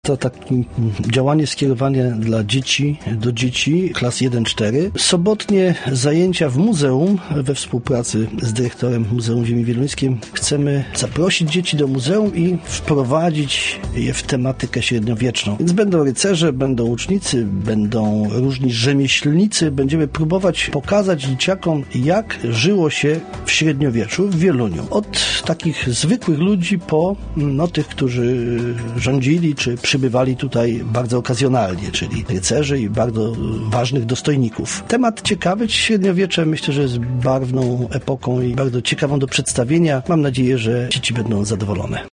O szczegółach nowego projektu edukacyjnego rozmawiano we wtorek na naszej antenie, podczas programu „Po dziewiątej”.